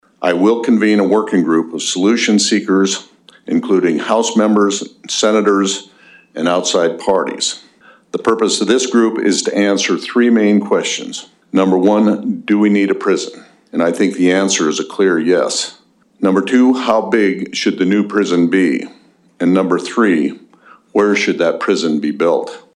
During a press conference on Thursday morning, South Dakota Governor Larry Rhoden announced “Project Prison Reset.”